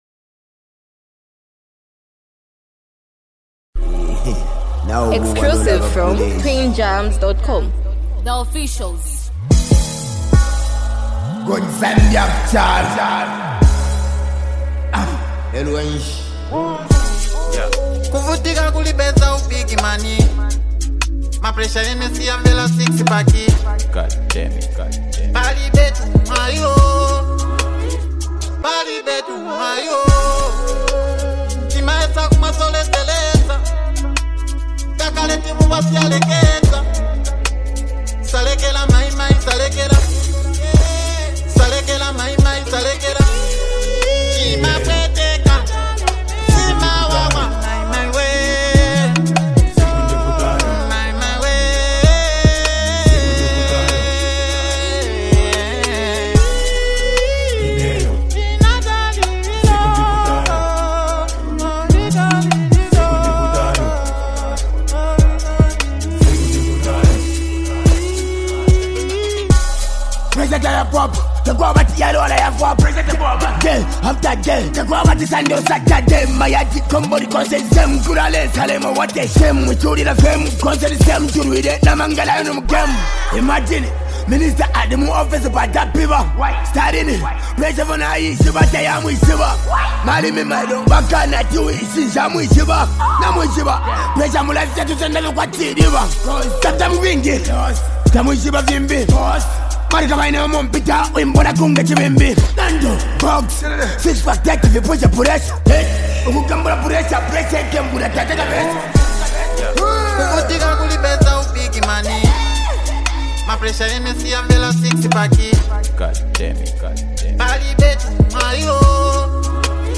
melodic and emotional hook